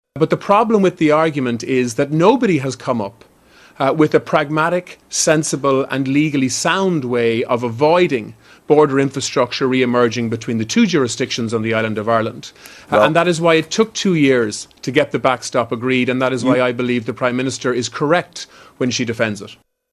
Speaking to the BBC, Foreign Affairs Minister, Simon Coveney says the British Prime Minister is right to defend the Backstop: